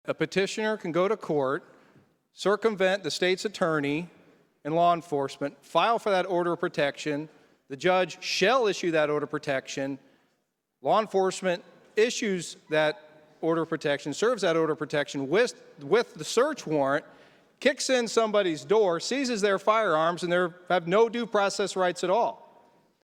During House debate before passage, State Representative Adam Niemerg opposed the bill.